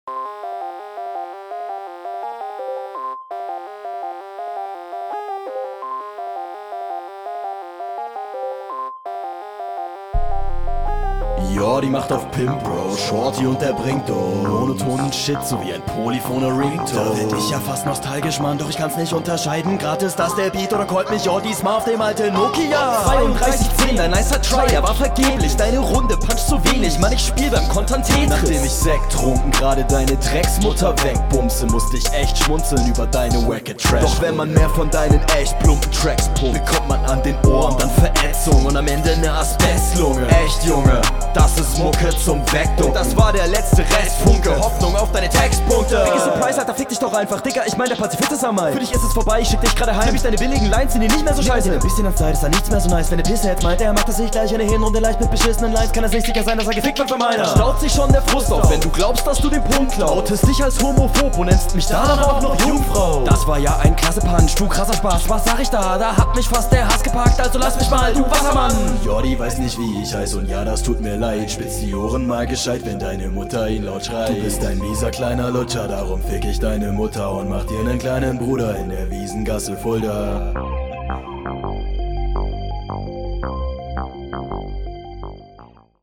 Stimmeinsatz finde ich besser sowie auch die Reime.